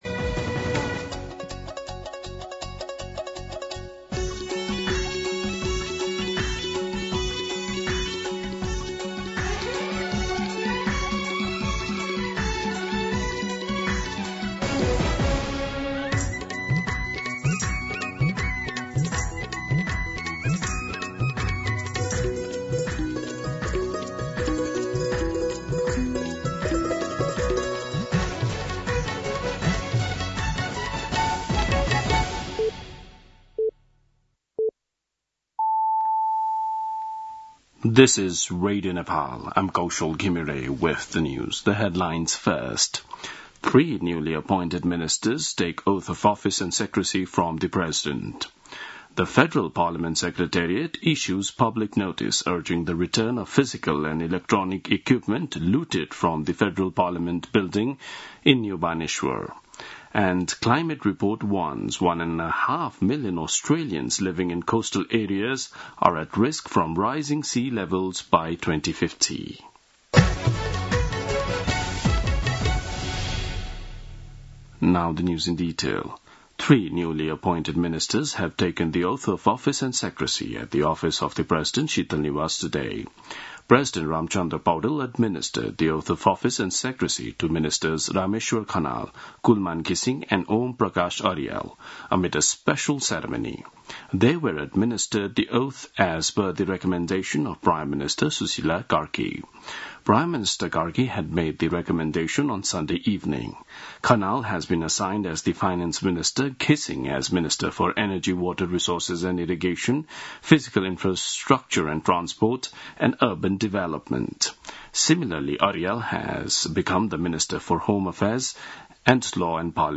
दिउँसो २ बजेको अङ्ग्रेजी समाचार : ३० भदौ , २०८२